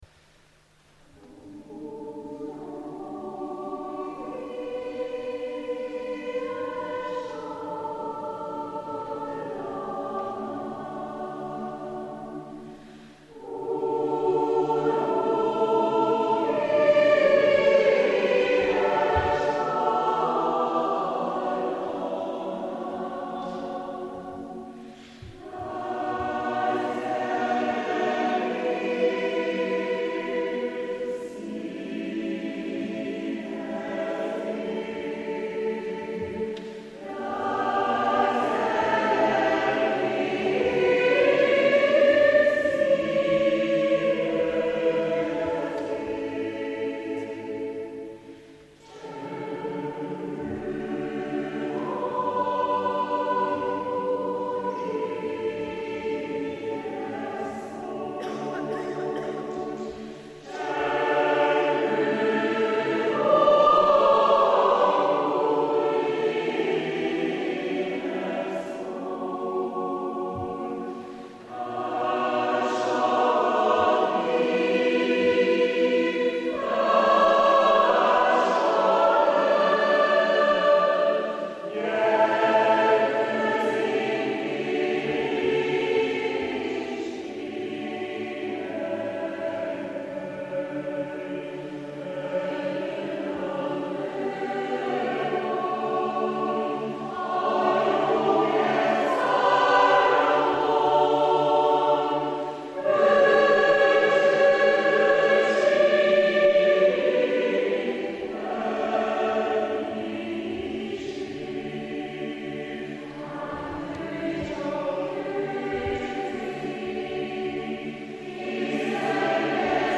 Aufnahme in der Kirche in Csongrád, 04.11.2013